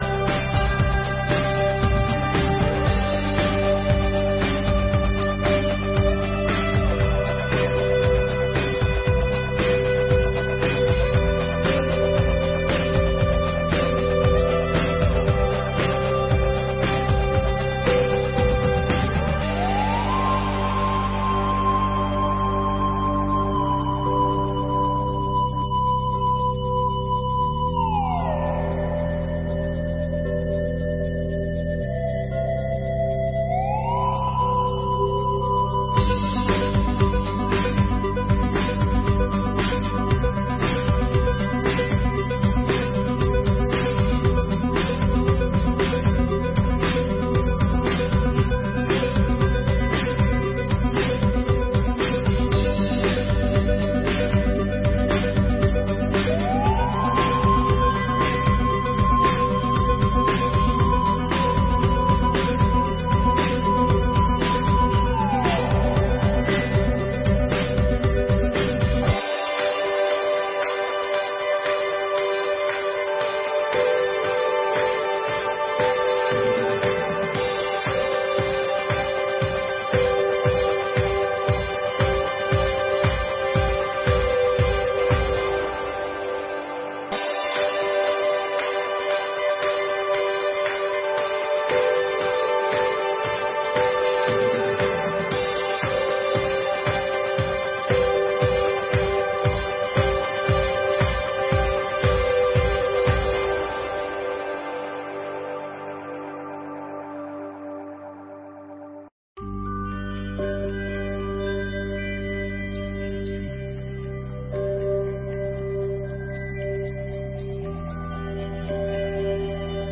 Sessões Solenes de 2023